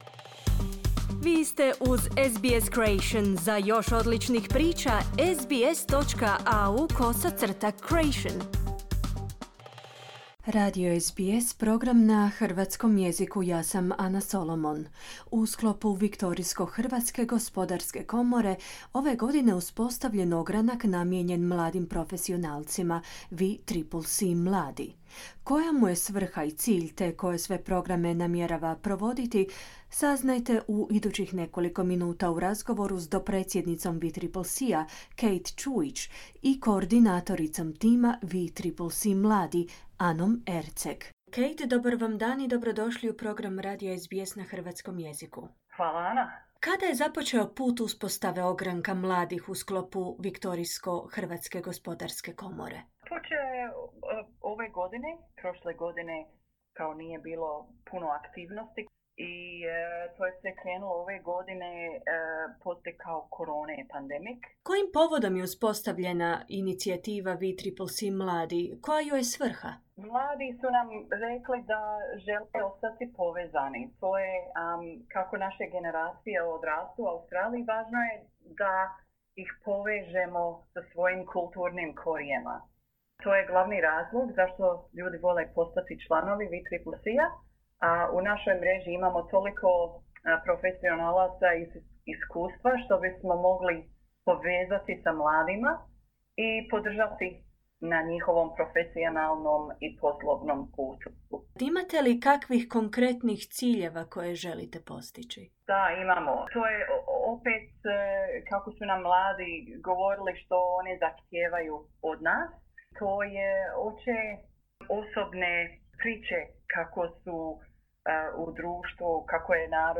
razgovoru